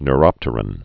(n-rŏptər-ən, ny-)